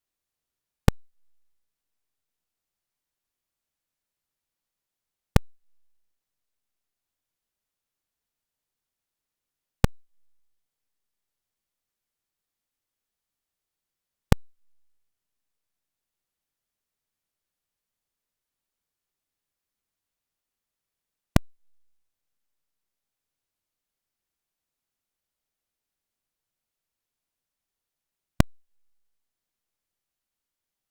Регулярность в 4.5с должна что-то означать.
Имеется в виду смешивание A+B+C. У меня в одном канале идет звук для тестирования, во втором - разделяющие щелчки. Одно на другое повлияло.
ulaxclick.flac